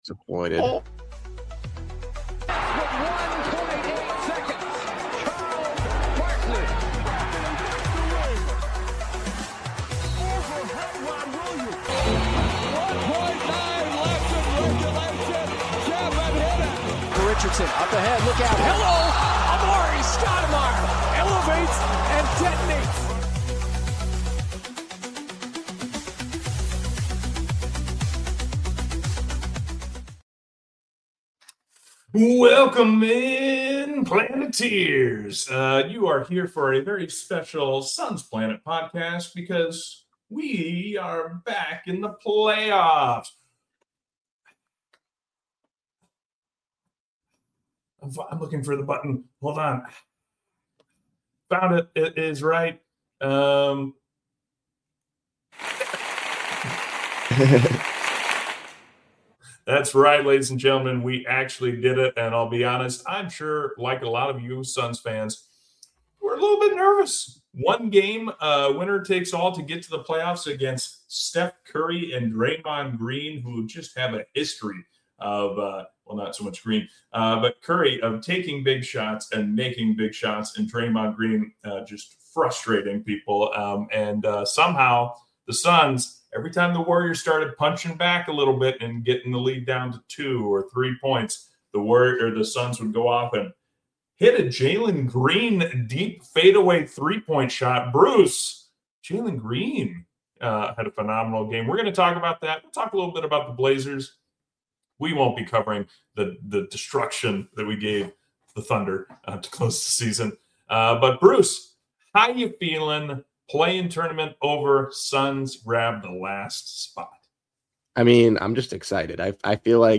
hosted by 3 long-time Suns fans